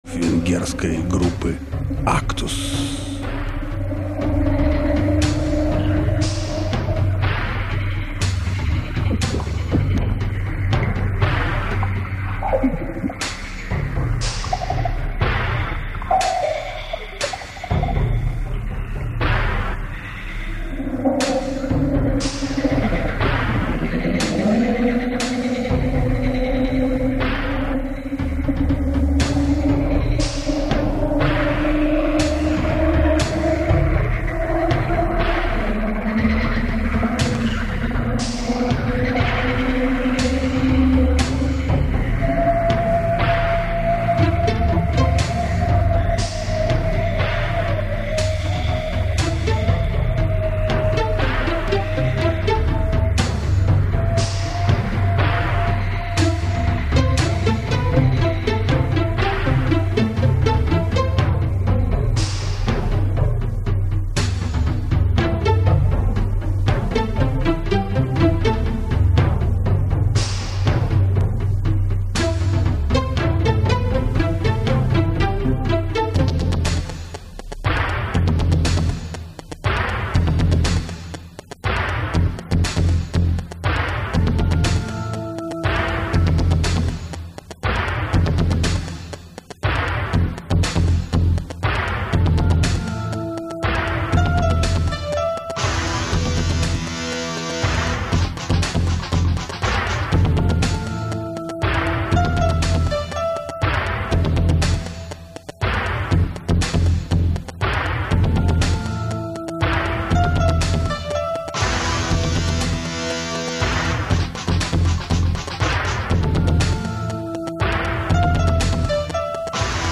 Да Спасибо, именно с Лягушками,  Звуки Природы, понимаш!